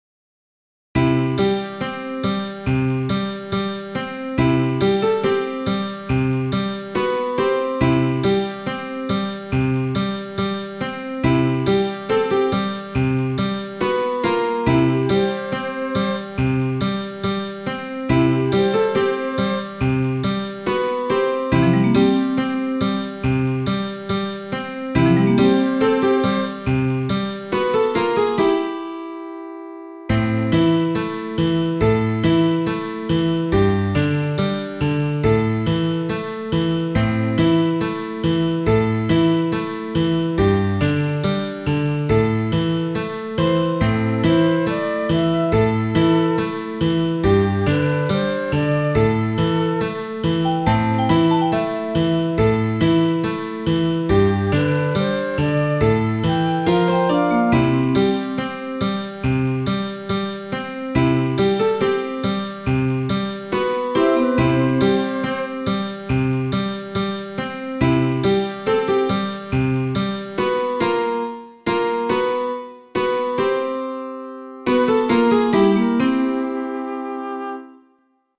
BALLADS